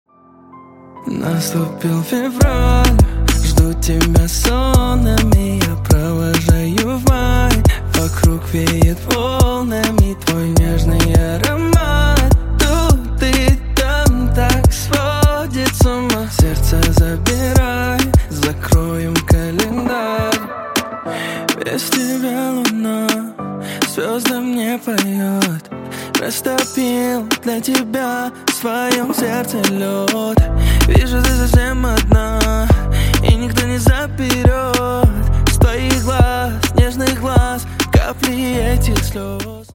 Поп Рингтоны